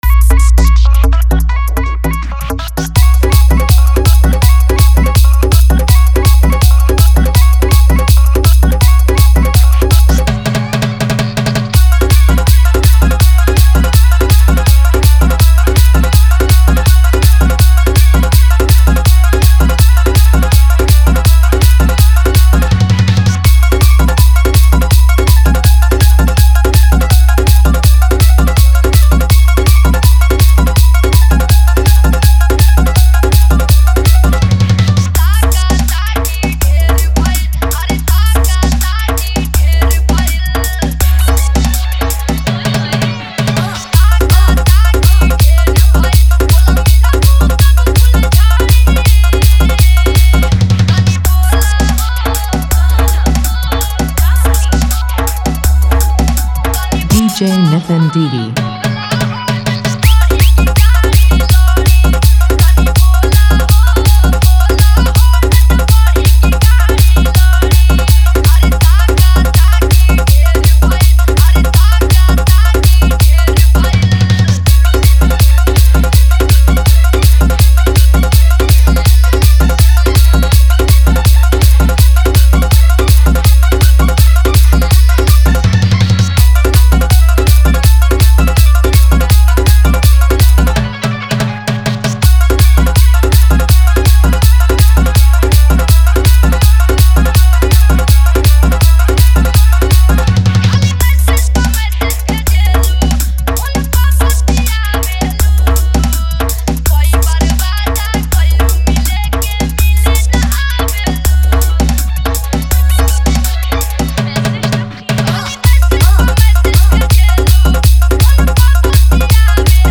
Single Dj Remix